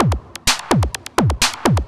DS 127-BPM B6.wav